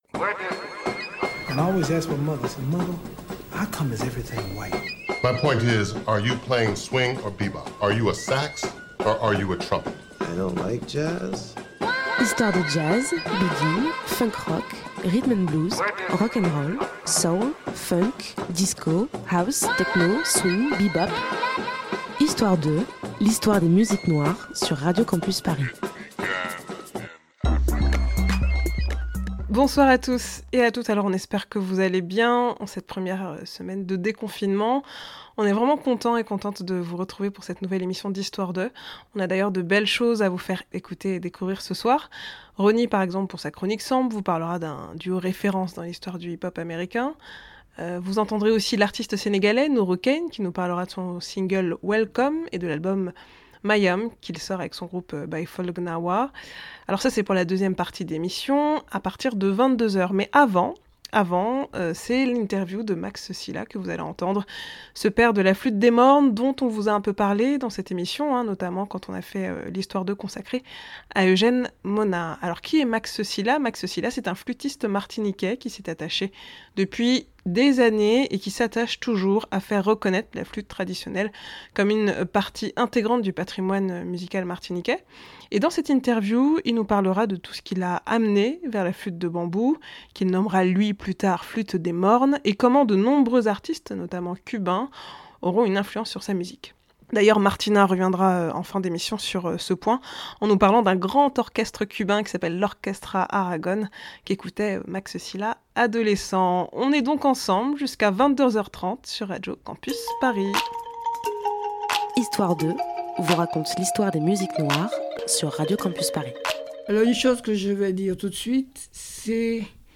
Une belle conversation parsemée d'anecdotes et de messages forts de sens...